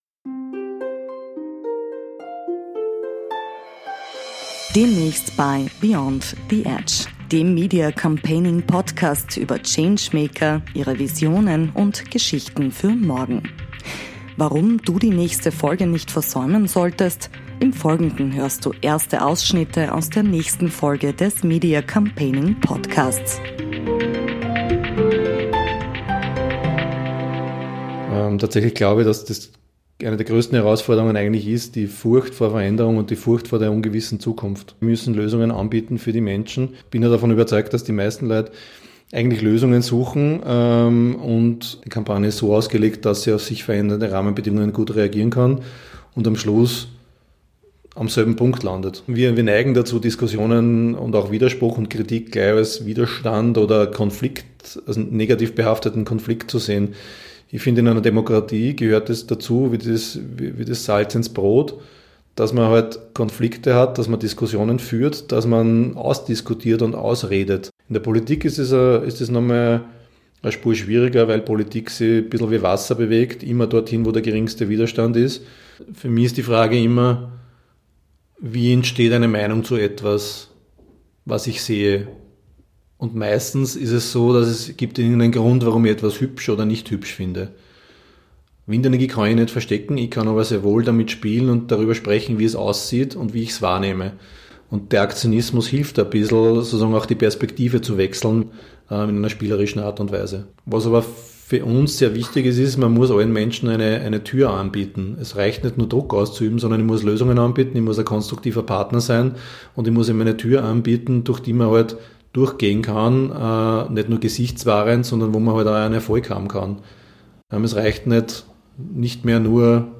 Erste Ausschnitte aus einem spannenden Gespräch mit einem wichtigen Changemaker Österreichs